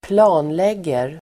Uttal: [²pl'a:nleg:er]